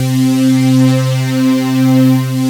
BIGPAD.wav